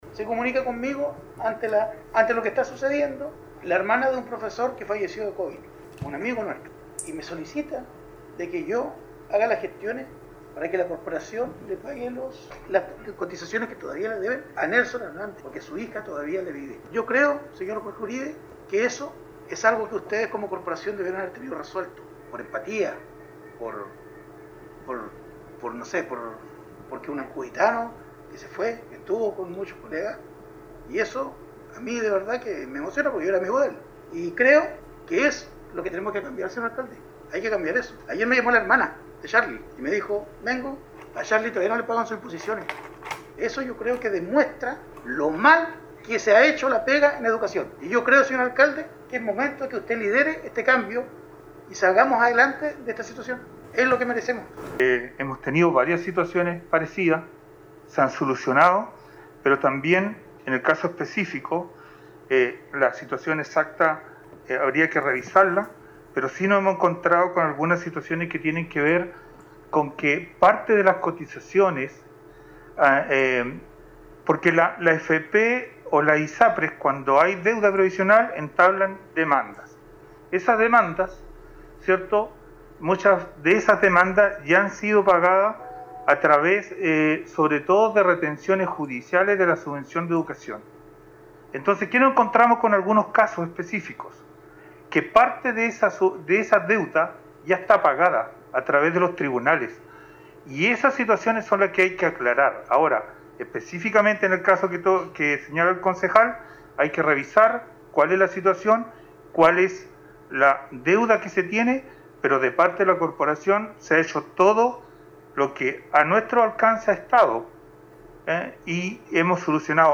En el contexto del conflicto que mantienen los gremios de la educación, y durante la realización del Concejo Municipal extraordinario realizado el día miércoles, el concejal Andrés Ibáñez dio a conocer una situación muy delicada que afecta a una familia de Ancud.
Escuchemos la secuencia de esas intervenciones.